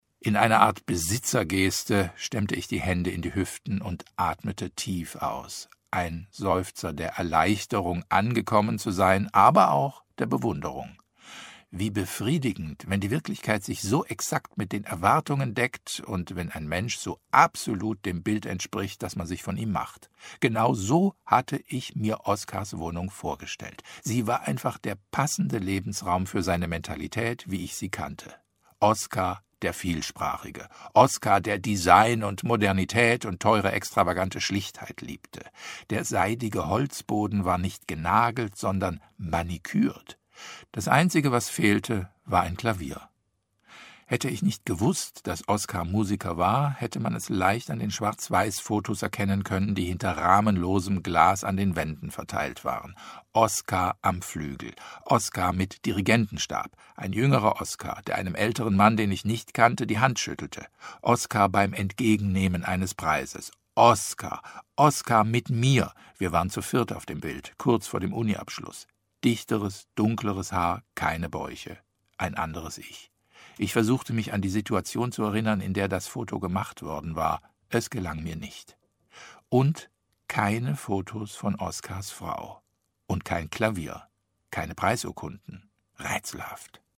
eLearning